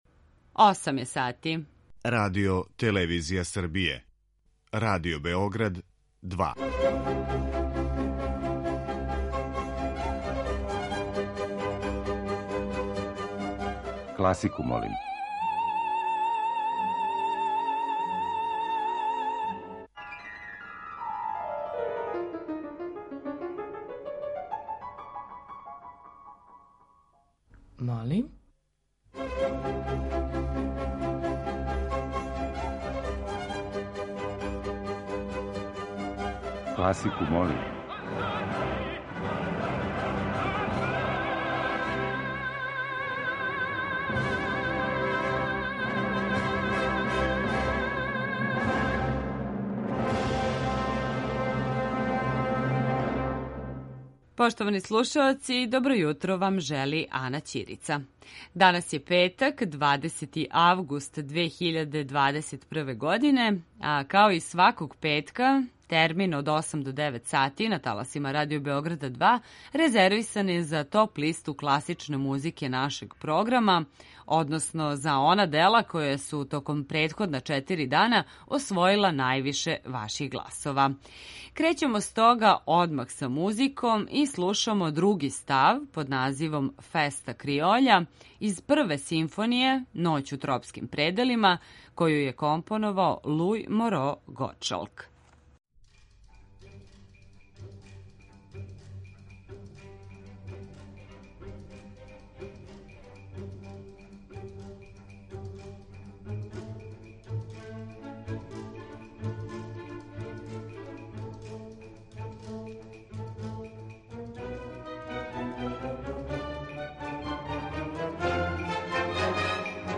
Топ-листа класичне музике